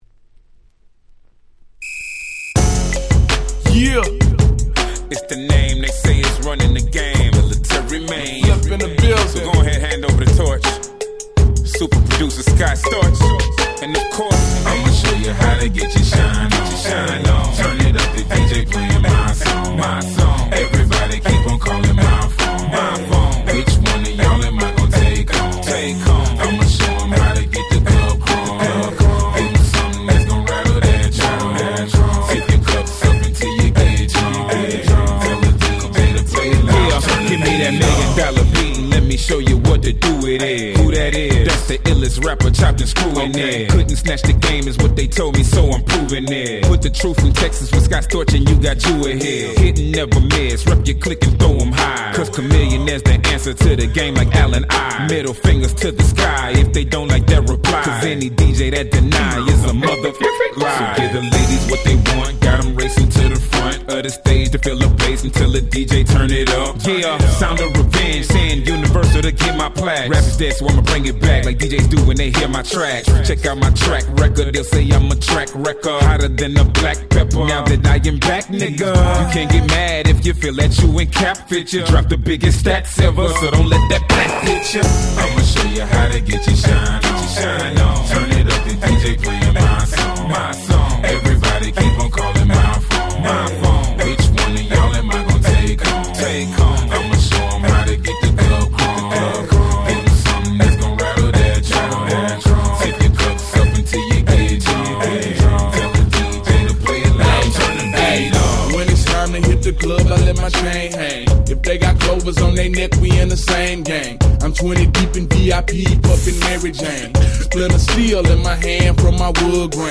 05' Big Hit Southern Hip Hop !!